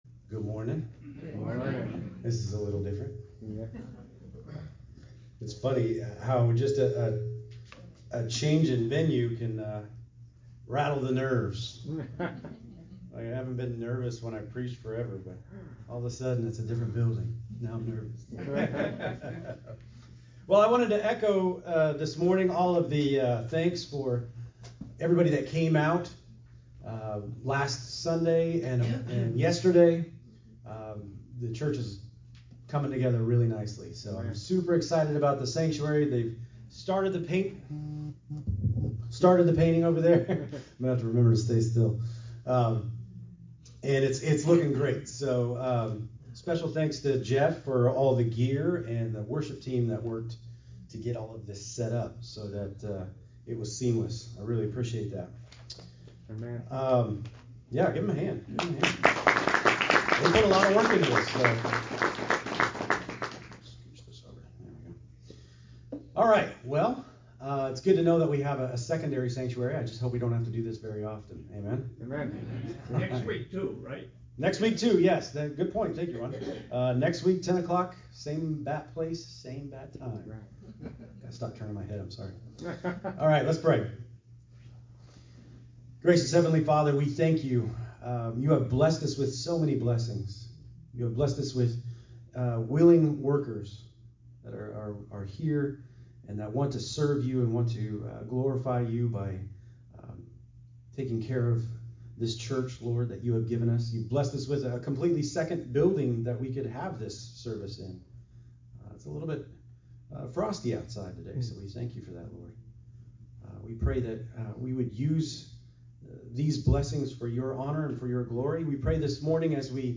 Sermons – Brentwood Bible Fellowship